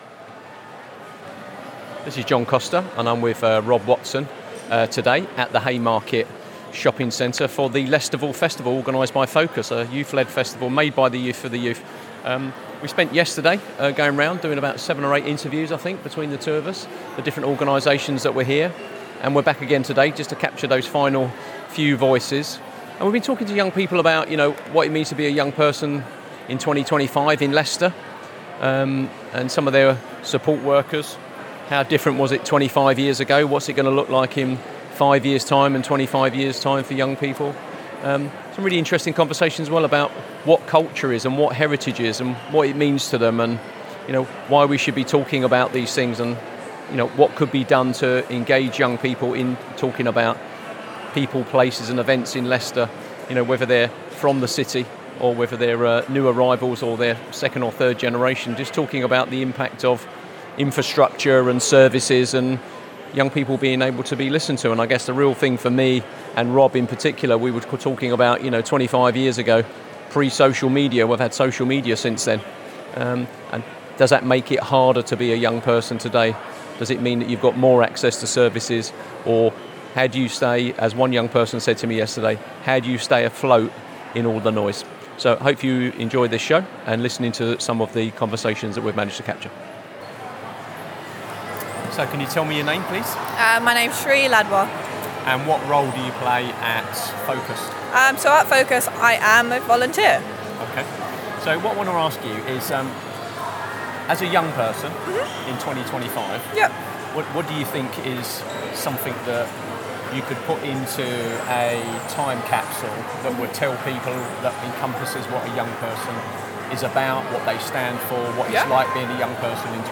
That was the focus of Spotlight on Heritage, recorded at the Haymarket Shopping Centre as part of a youth-led festival organised by Focus. The event brought together young people, volunteers, and community workers to talk about what heritage means to them and how their experiences in Leicester shape their sense of identity.